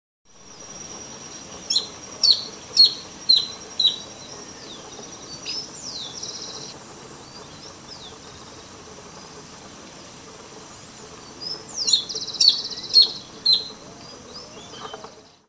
Tio-tio (Phacellodomus striaticollis)
Nome em Inglês: Freckle-breasted Thornbird
Fase da vida: Adulto
Localidade ou área protegida: Reserva Natural del Pilar
Condição: Selvagem
Certeza: Gravado Vocal
espinero-pecho-manchado.mp3